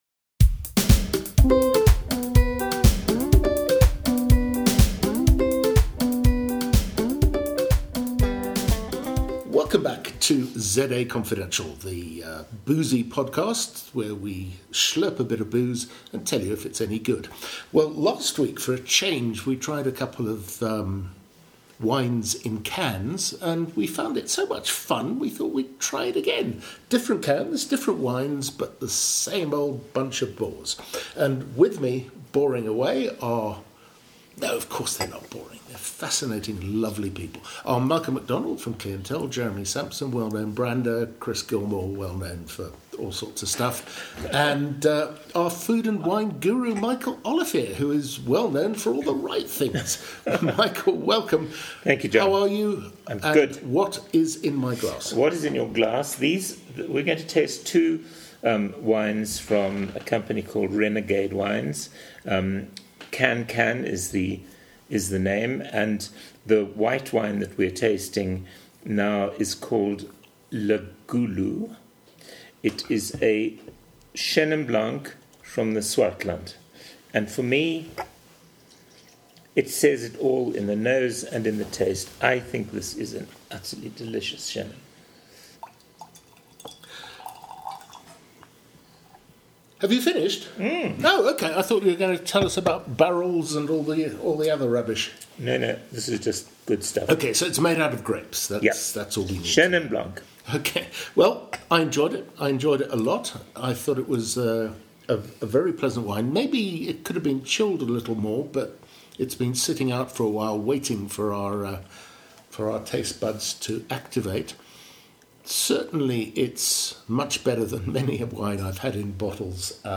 Podcast wine tasting: a White and a Rosé from CanCan